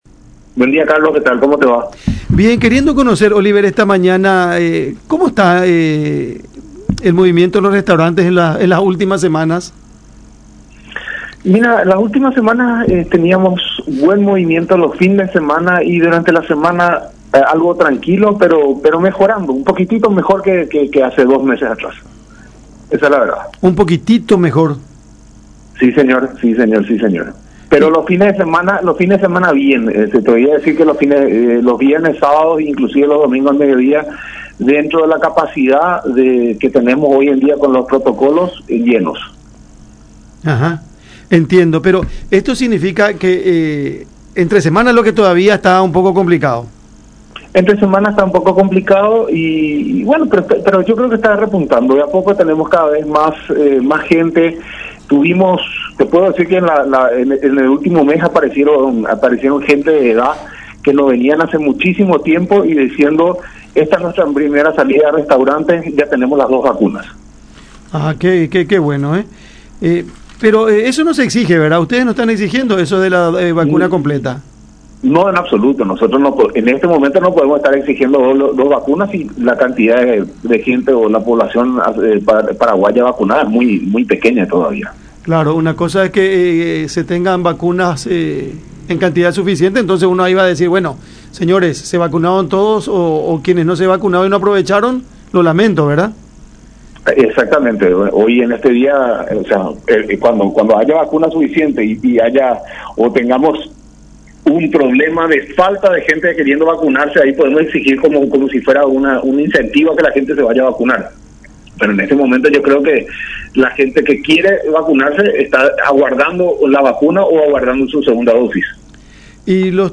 “Pero los fines de semanas bien, los viernes y sábados, inclusive los domingos al medio día, dentro de la capacidad que Tenes, llenos” indicó en dialogo con La Unión R800 AM.